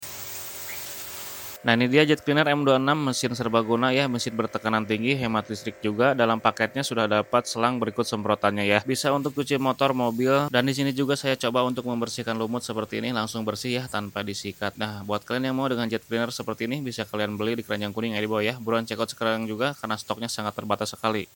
Jet Cleaner M26 Tekanan Tinggi Sound Effects Free Download